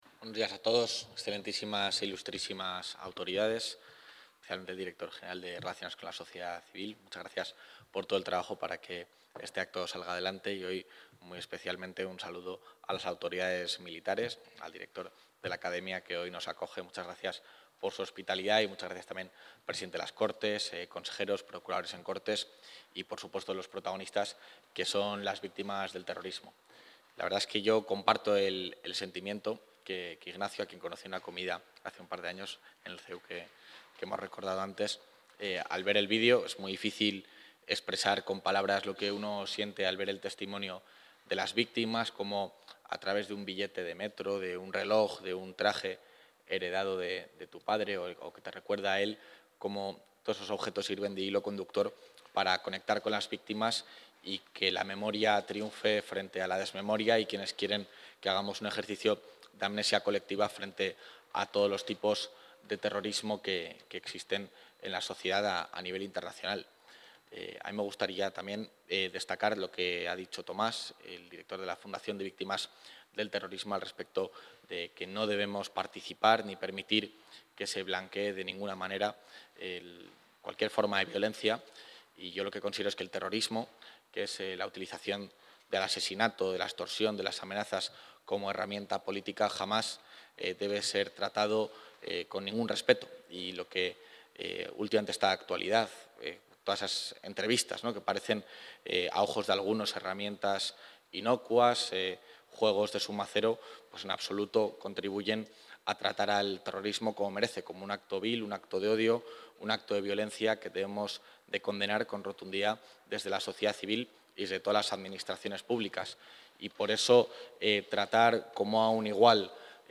Intervención del vicepresidente.
El vicepresidente de la Junta de Castilla y León ha asistido hoy en Valladolid a la inauguración de la muestra ‘Memories’, organizada por Fundación Centro Memorial Víctimas del Terrorismo y la Fundación Víctimas.